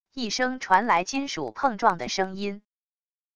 一声传来金属碰撞的声音wav音频